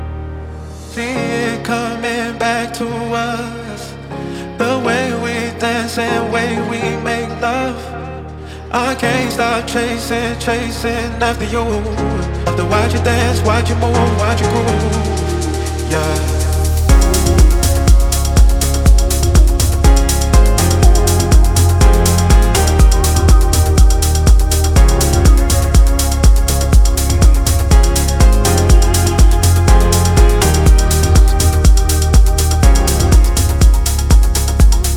Электроника — это про будущее, которое уже здесь.
Жанр: Электроника